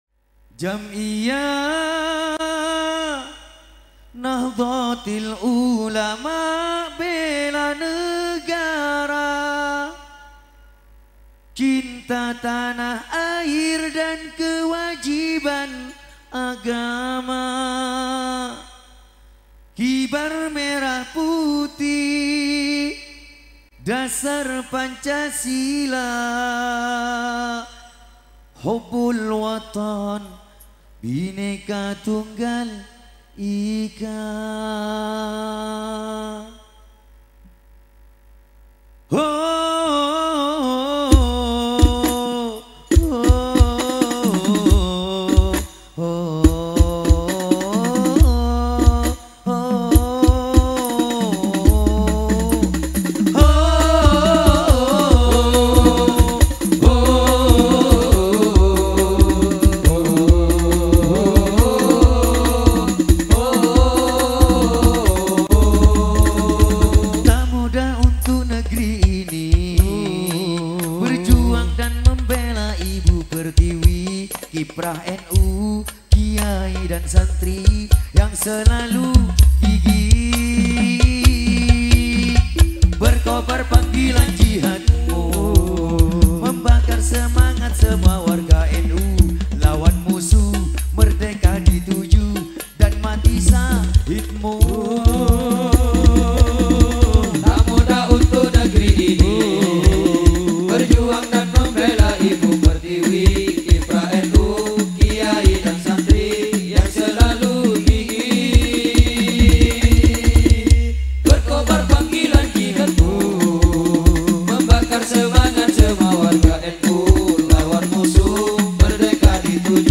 Blog Tempat Berbagi Sholawat Mulai Dari Lirik Sholawat